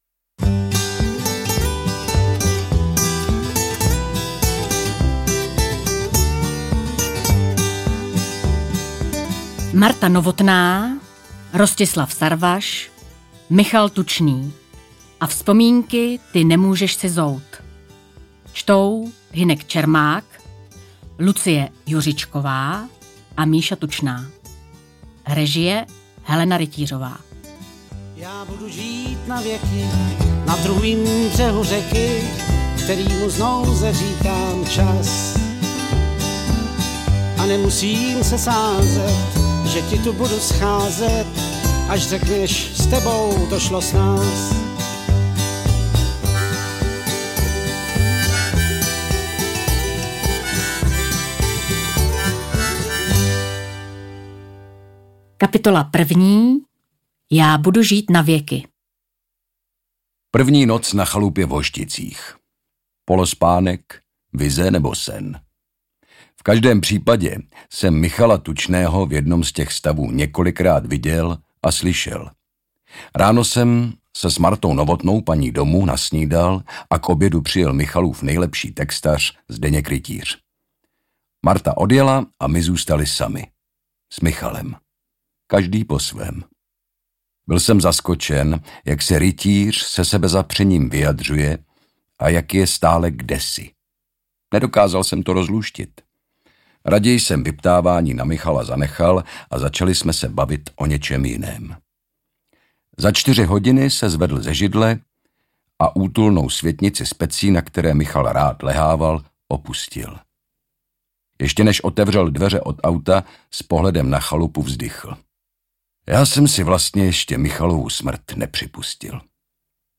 Interpreti:  Hynek Čermák, Lucie Juřičková, Lucie Juřičková, Michal Tučný
Poutavě vyprávěný životopis jednoho z nejvýznamnějších představitelů české country hudby Audiokniha přináší zasvěcený a plnohodnotný obraz legendárního zpěváka.